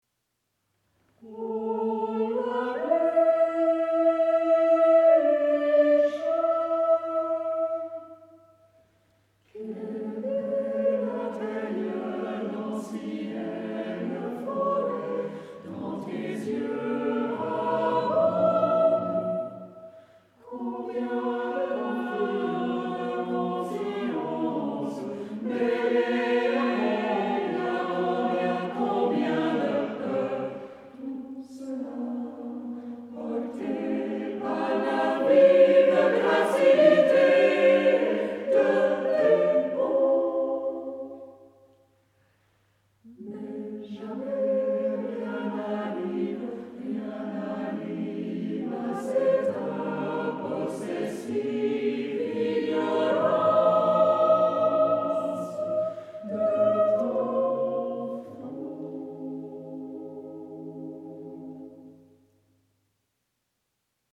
Concert du 22 octobre 2017
Temple de Dombresson